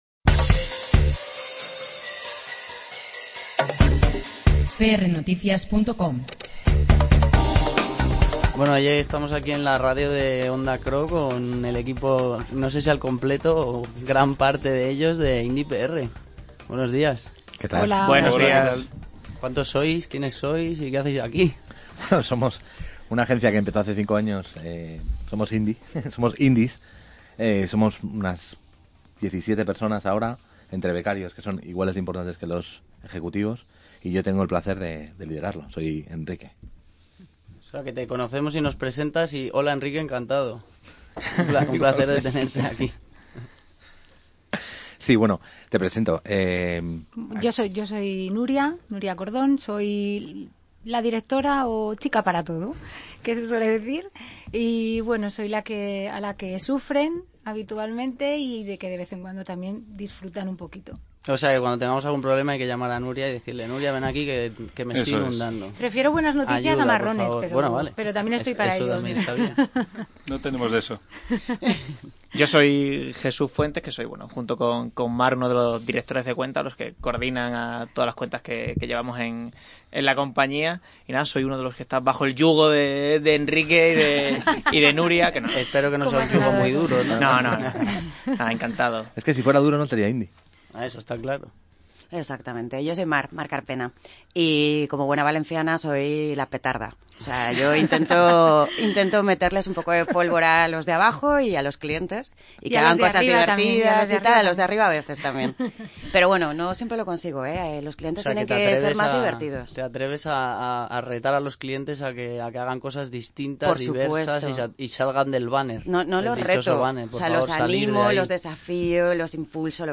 Durante su visita a prnoticias, el equipo de Indie PR fue entrevistado en Onda Cro . Hablaron de comunicación, marketing, revolución y futuro.